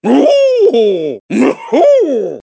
One of Donkey Kong's voice clips in Mario Kart 7